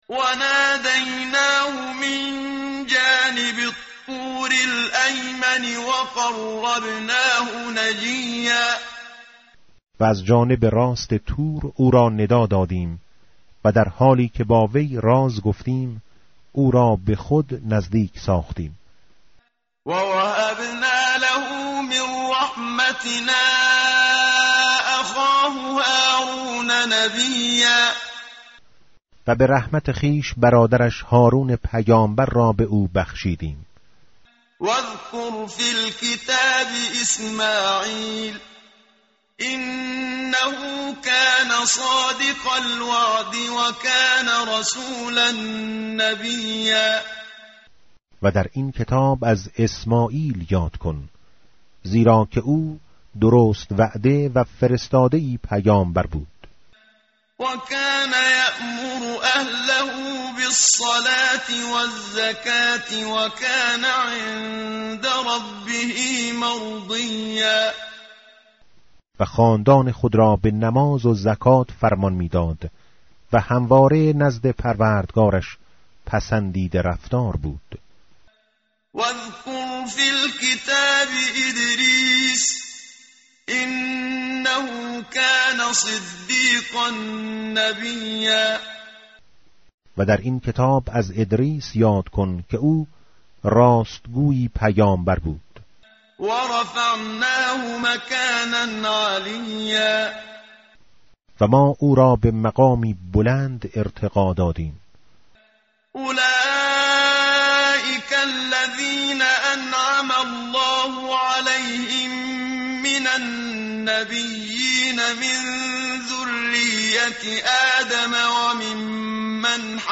tartil_menshavi va tarjome_Page_309.mp3